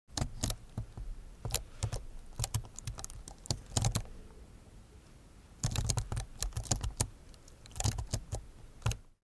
جلوه های صوتی
دانلود صدای کیبورد 11 از ساعد نیوز با لینک مستقیم و کیفیت بالا